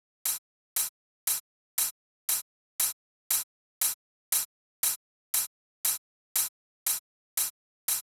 28 Hihat.wav